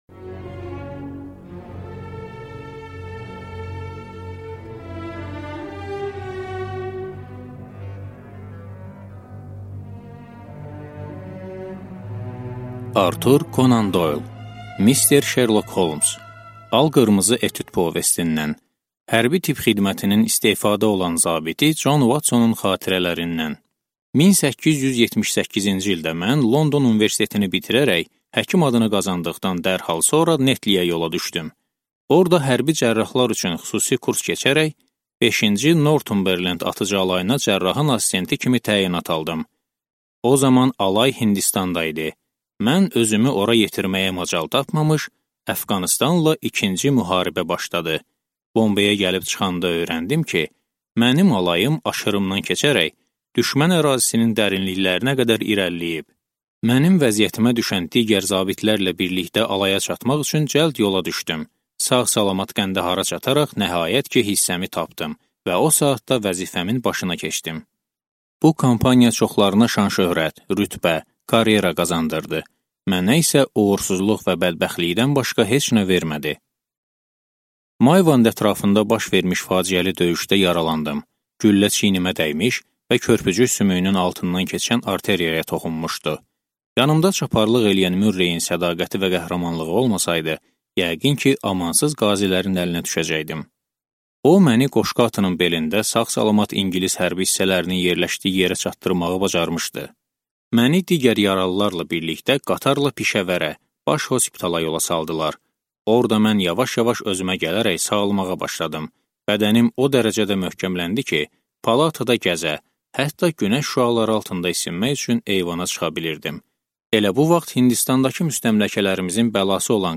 Аудиокнига Şerlok Holmsun macəraları | Библиотека аудиокниг